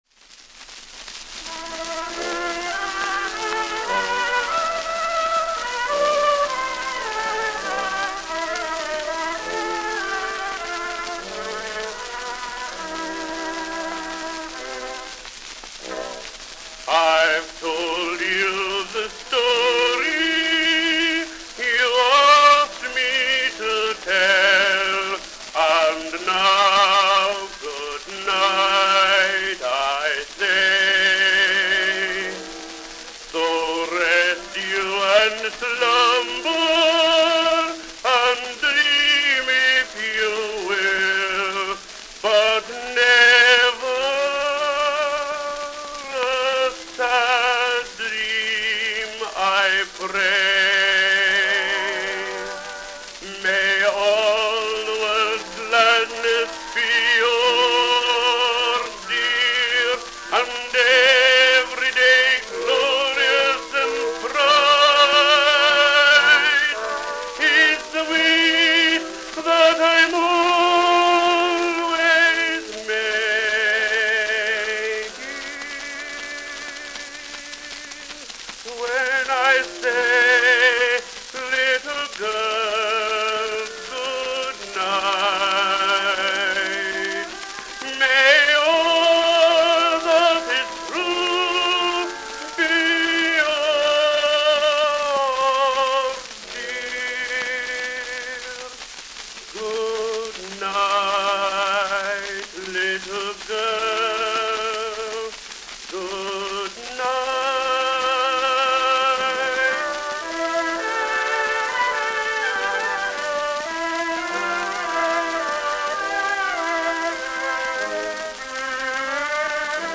Tenor solo with orchestra accompaniment.
Popular music—1901-1910.